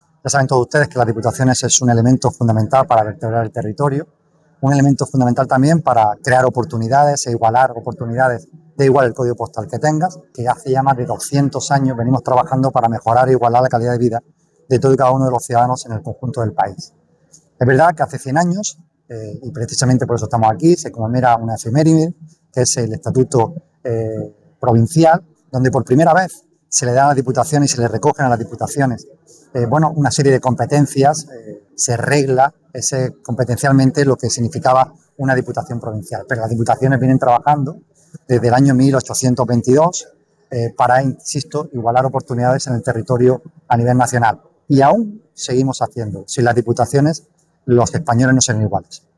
El presidente de la Diputación de Almería, Javier A. García, defiende en la Diputación de Huelva el papel social de las instituciones locales en el centenario del Estatuto Provincial de 1925